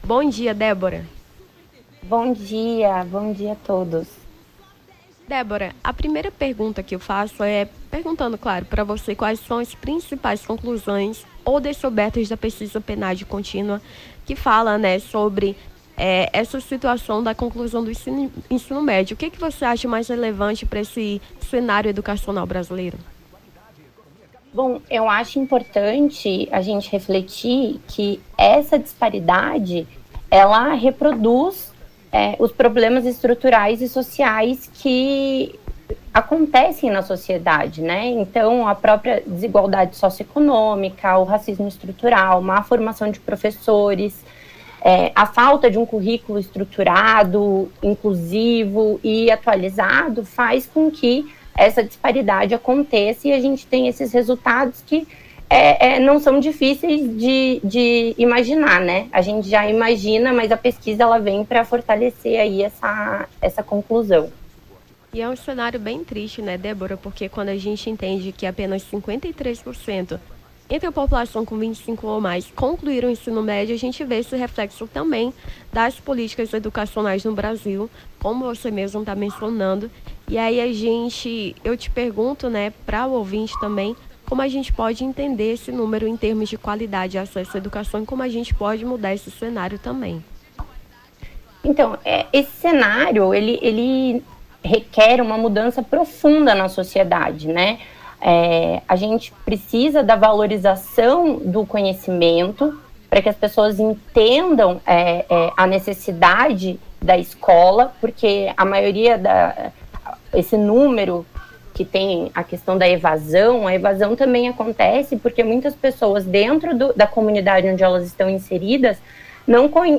Nome do Artista - CENSURA - ENTREVISTA (EDUCACAO BRASIL) 17-10-23.mp3